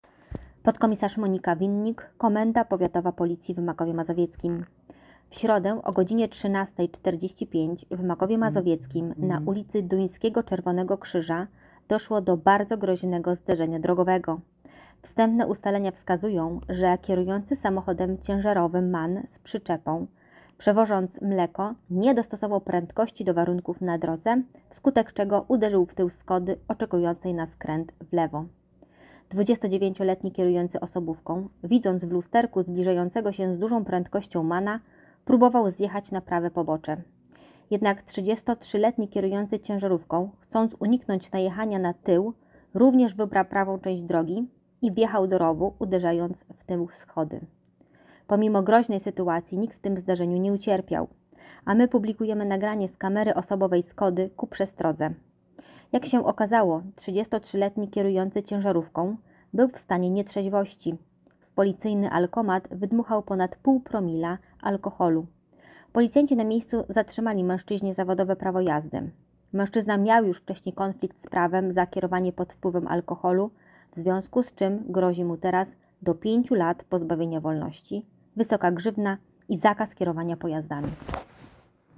Nagranie audio Wypowiedź